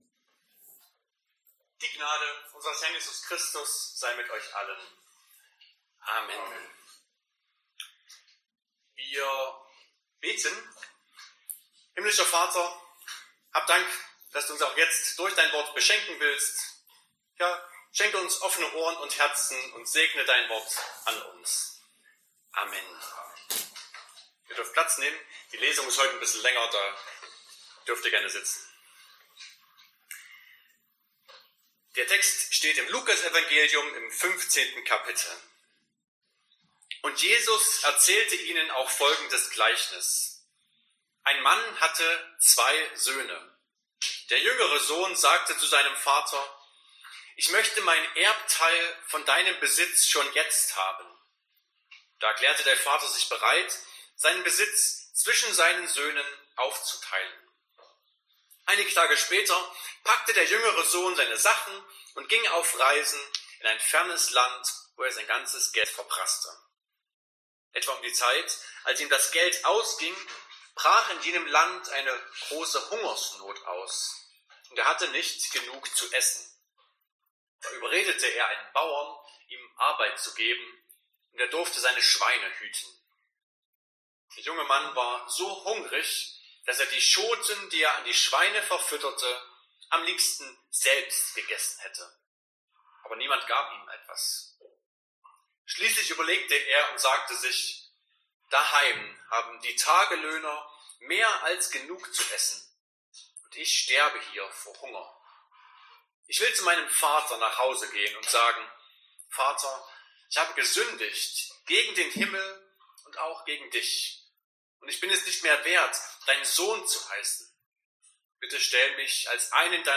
Buß- und Bettag Passage: Lukas 15,11-24 Verkündigungsart: Predigt « Vorletzter Sonntag im Kirchenjahr 2024 1.